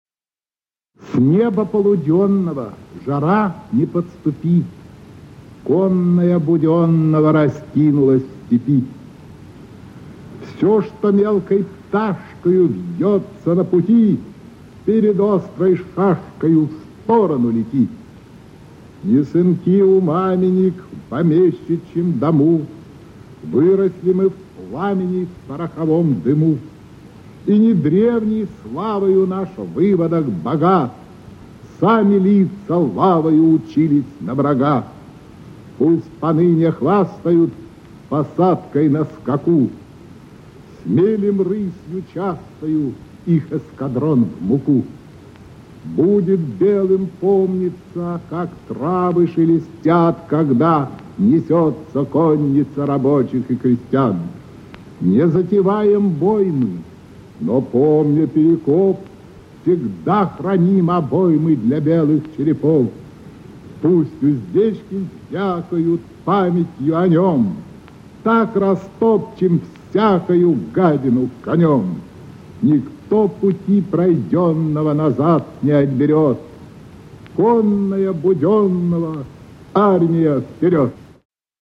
1. «Николай Асеев – Марш Буденного (читает автор)» /
Aseev-Marsh-Budennogo-chitaet-avtor-stih-club-ru.mp3